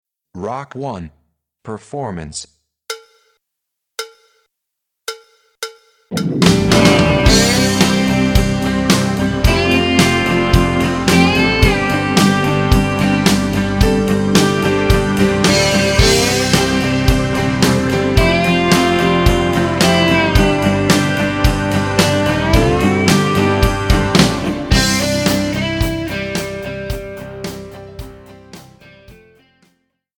Voicing: DrumSet/CD